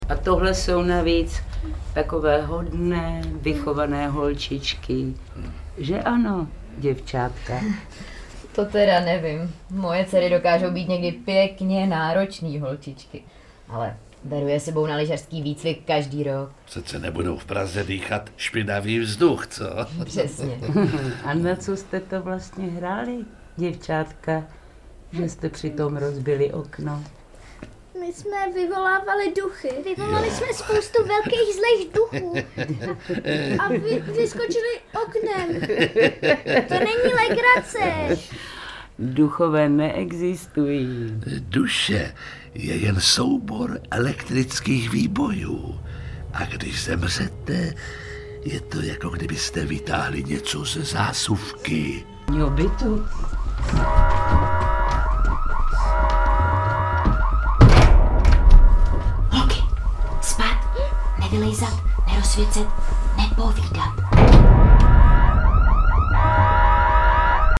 Seriál byl natočen speciální binaurální technikou. Všechny hlasy a zvuky tak uslyšíte prostorově a plasticky, jako by postavy stály třeba přímo za vámi.
• Jiří Lábus (Cháron); Pavla Tomicová (Krchová)
Horor pro 10+